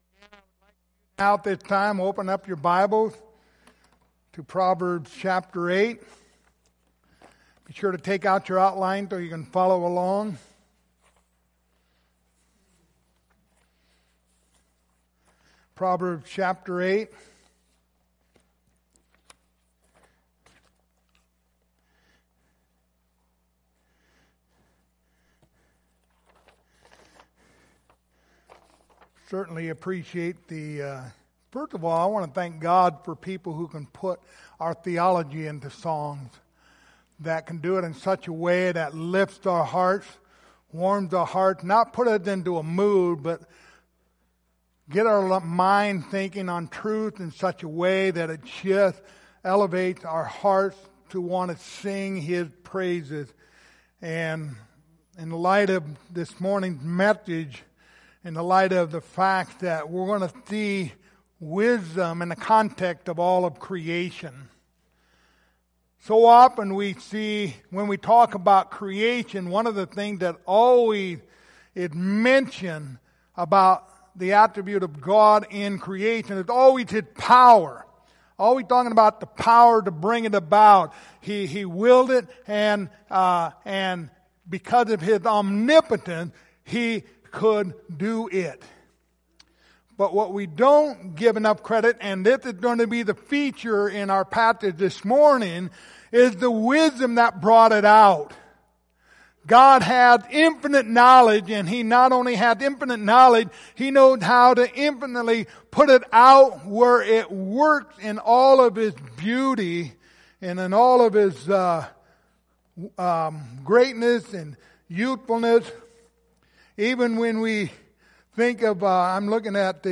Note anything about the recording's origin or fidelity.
Series: The Book of Proverbs Passage: Proverbs 8:22-36 Service Type: Sunday Morning